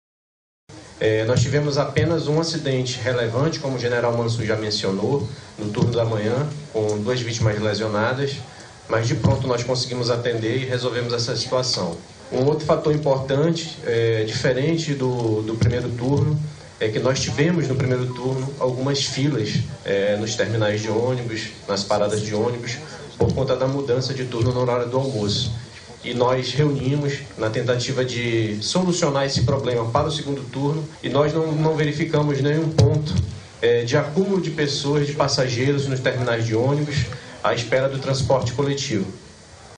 Durante coletiva de imprensa, órgãos envolvidos nas Eleições 2022 divulgaram dados.